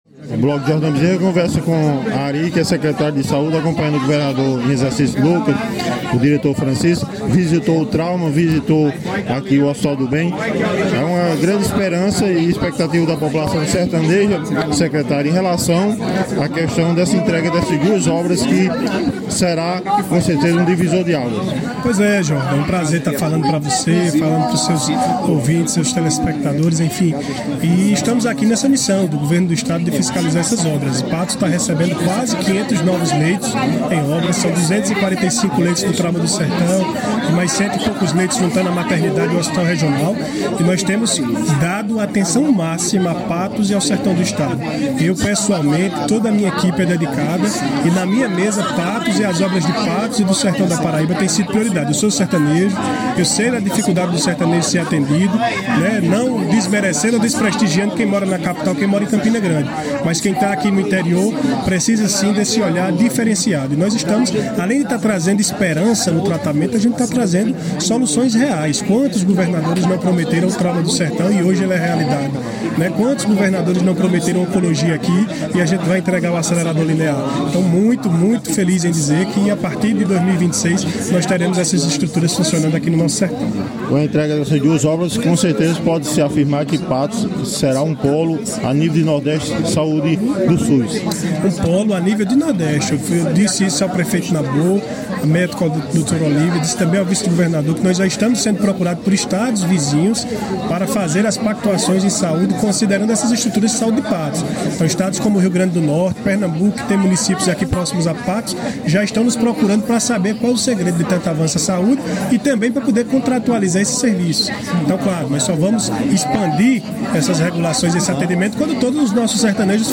Na ocasião, o secretário de Saúde do Estado, Ari Reis, concedeu entrevista e destacou os investimentos do Governo da Paraíba na saúde da região.